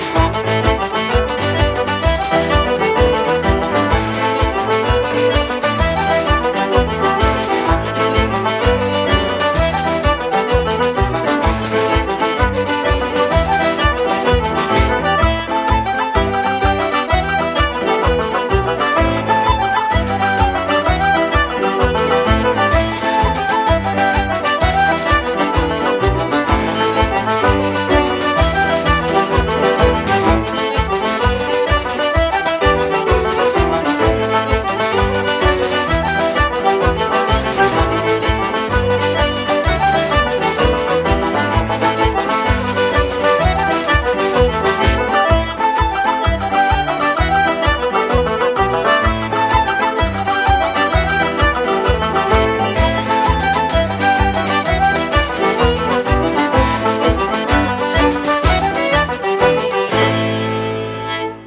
Jigs
accordion
flute
fiddle
banjo
piano
drums